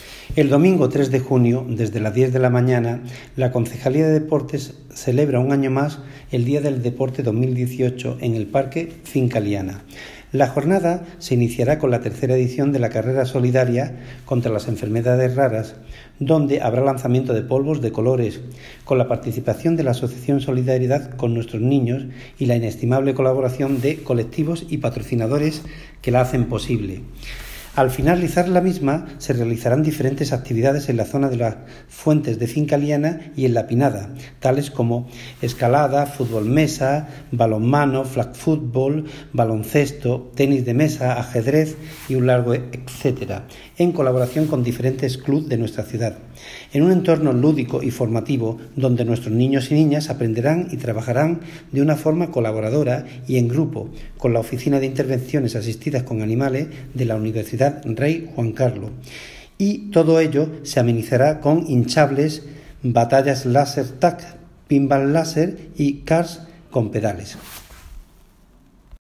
Audio - Agustín Martín (Concejal de Deportes, Obras, Infraestructuras y Mantenimiento de vías públicas)